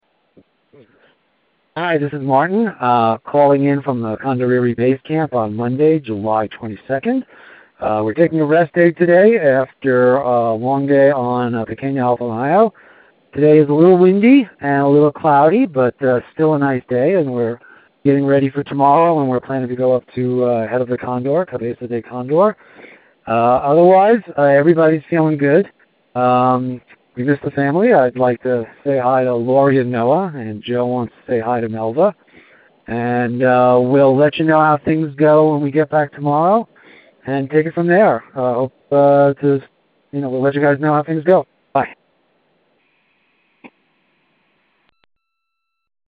July 22, 2013 – Rest Day at the Condoriri Base Camp